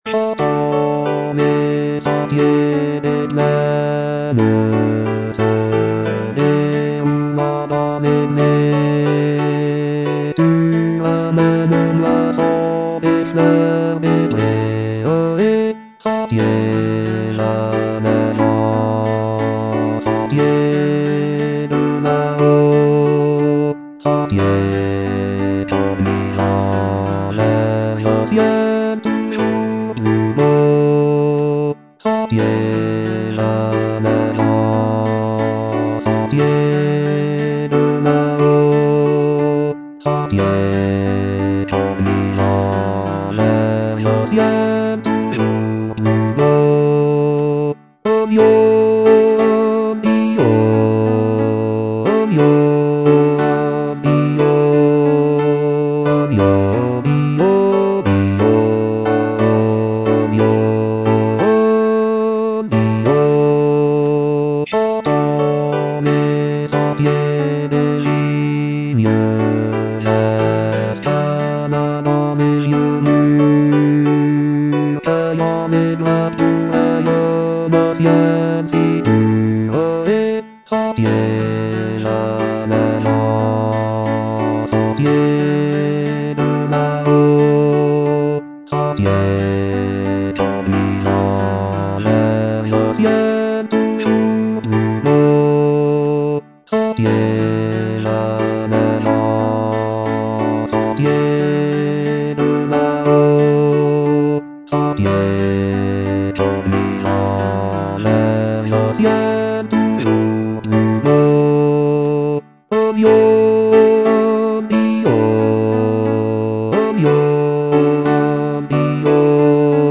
Bass I (Voice with metronome)